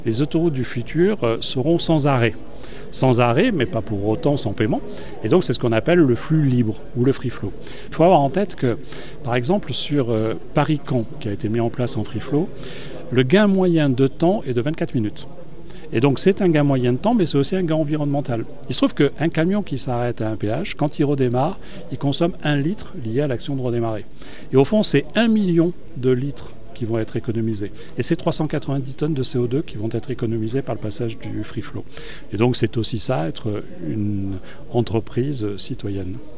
C'est ce qu'explique Christophe Castaner, le Président de la société autoroutière ATMB :